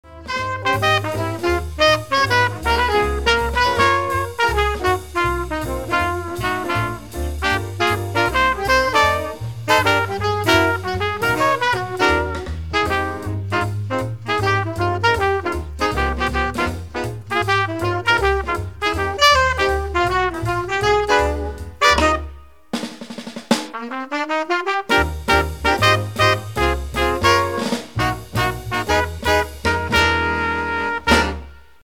• Качество: 192, Stereo
без слов
инструментальные
приятные
джаз
Приятная ретро-музыка с мелодичным саксофоном